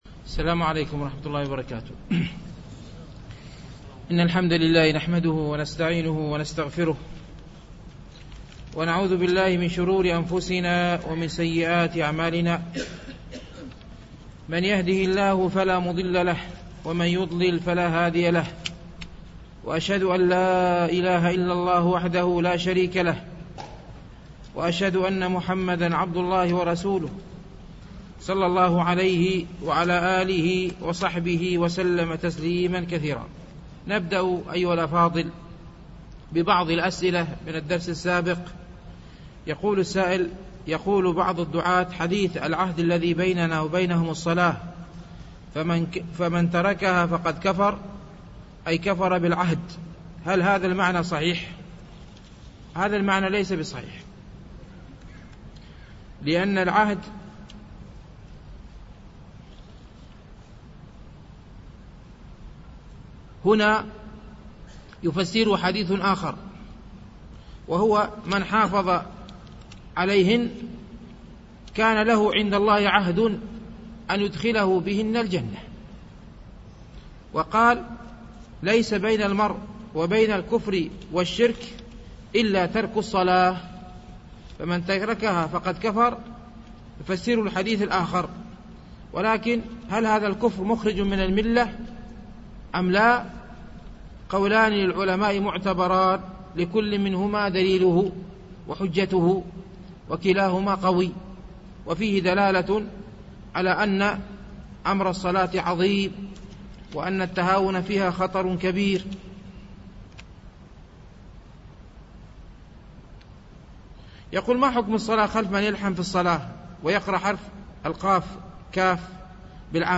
شرح رياض الصالحين - الدرس الخامس والستون بعد المئة